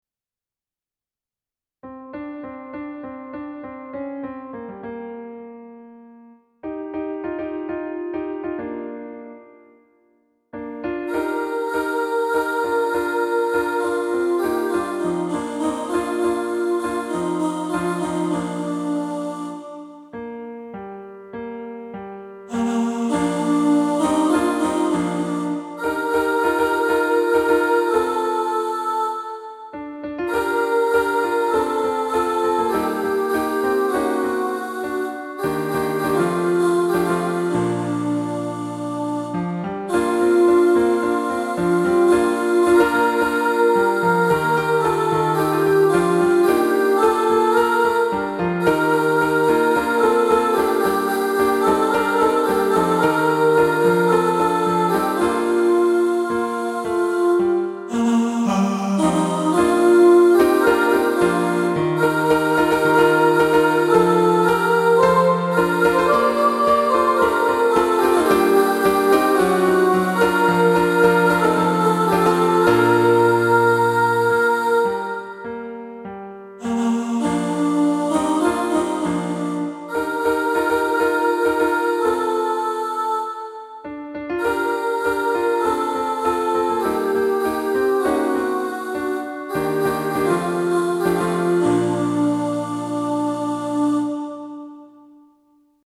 "Cups-Soprano".
Cups-Soprano.mp3